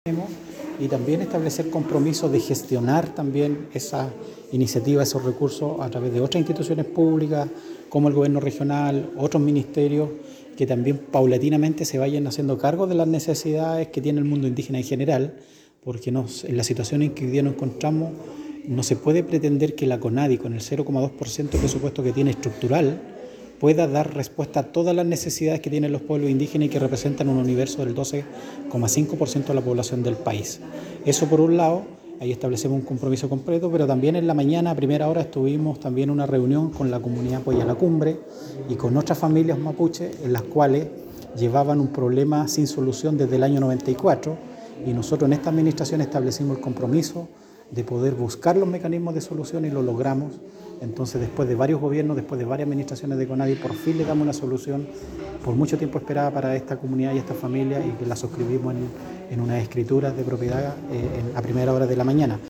El Director de Conadi, indicó que se busca trabajar en conjunto con otras instituciones como el Gobierno Regional, ya que con el presupuesto de 0.2% que recibe el organismo no es viable poder responder a todas las demandas de las comunidades de todo Chile.